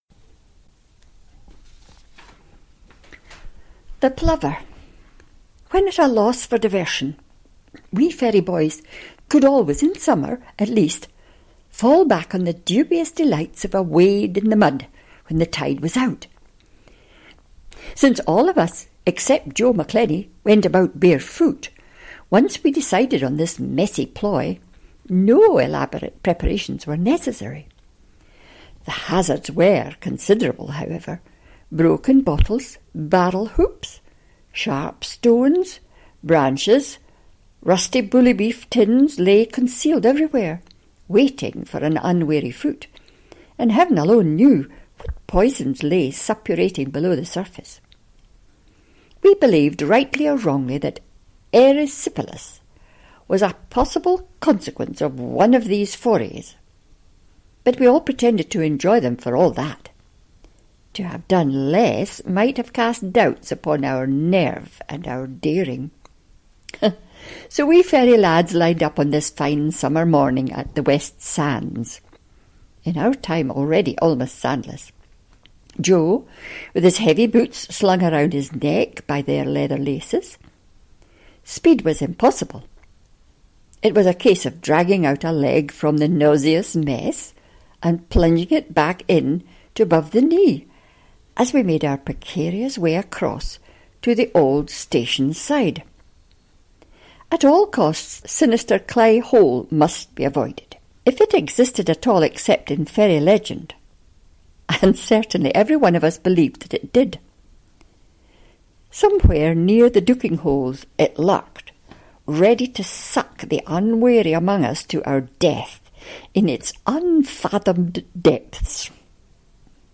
Ferry Yarns – narrated